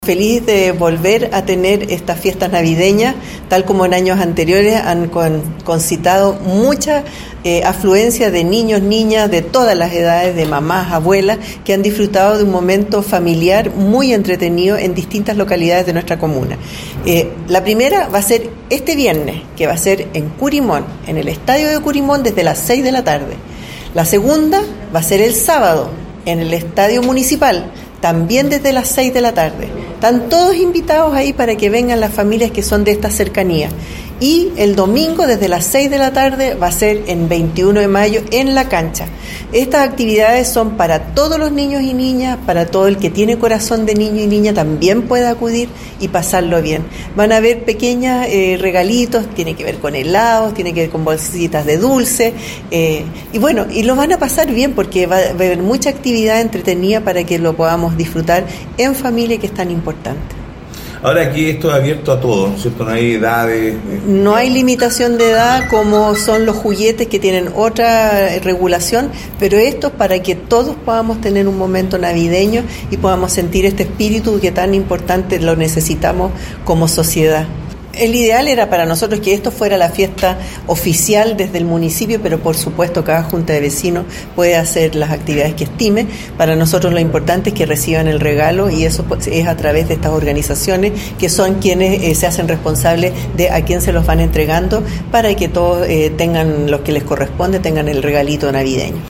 La Alcaldesa Carmen Castillo, entregó el programa completo.
ALCALDESA-FIESTAS-DE-NAVIDAD.mp3